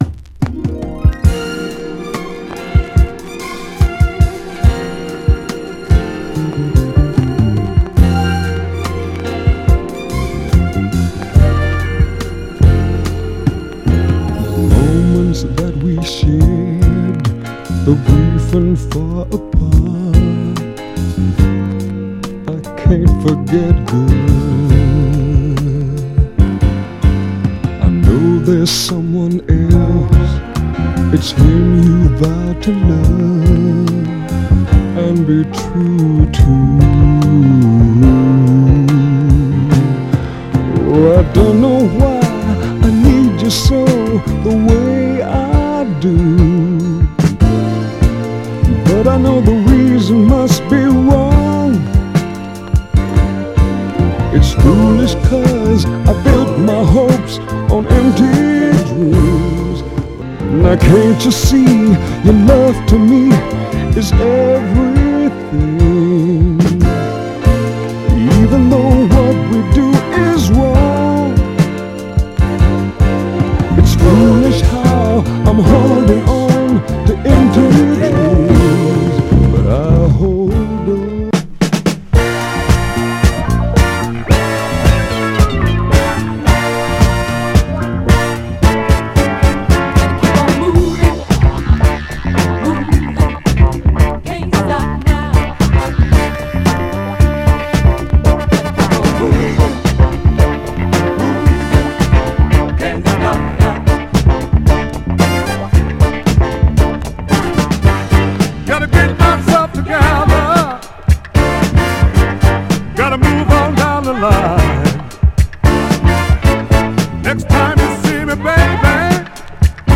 スウェイなグルーヴがカッコいいファンク・ダンサー
ただし(特に静かなB面に)このタイトル特有のプレス起因によるバックグラウンド・ノイズあり。
※試聴音源は実際にお送りする商品から録音したものです※